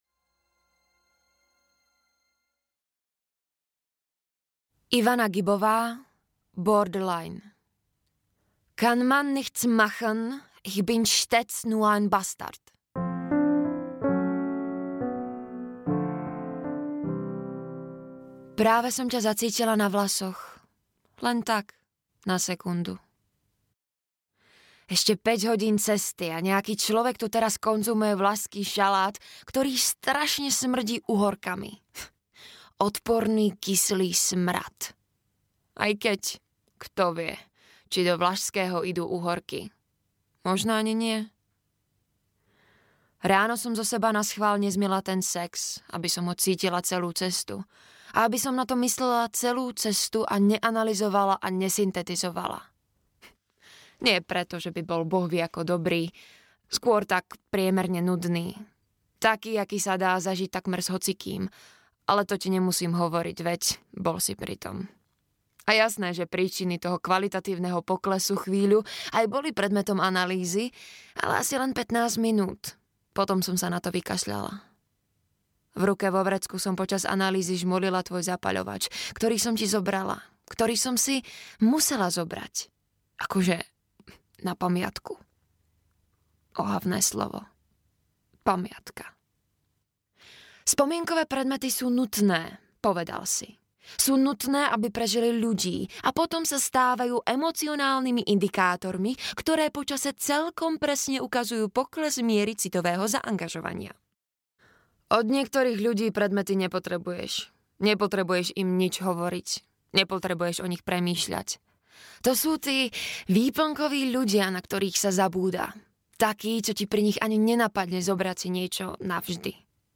Borderline audiokniha
Ukázka z knihy